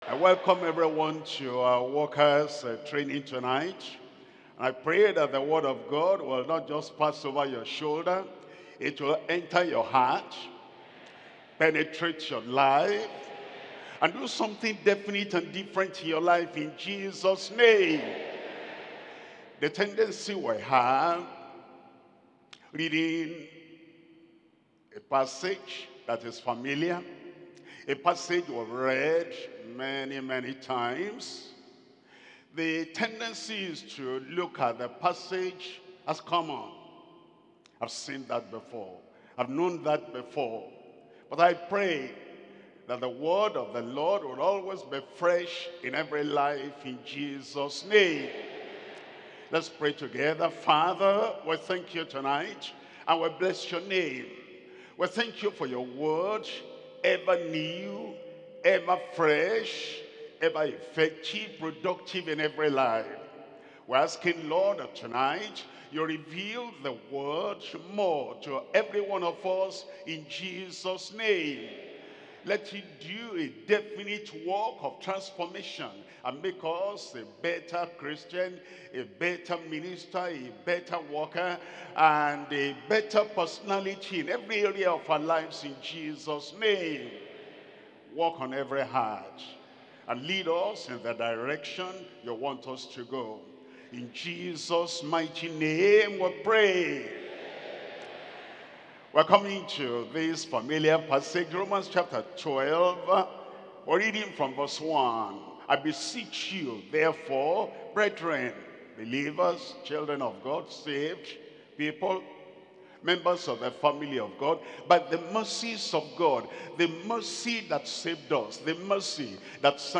Sermons – Deeper Christian Life Ministry, Greater Manchester